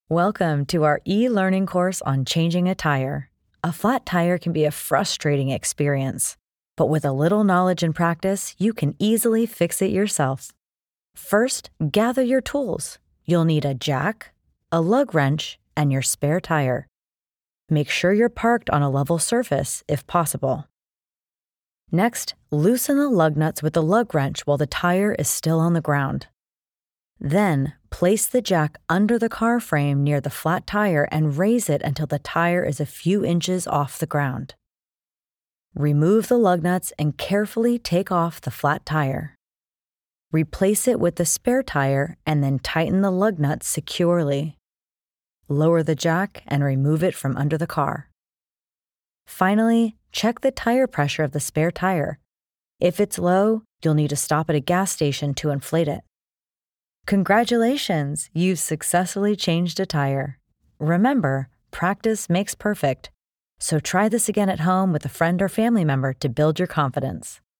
If you're looking for an English American female with an engaging, friendly, warm voice to grab and keep your listener's attention, I'm your gal.
eLearning voice over demo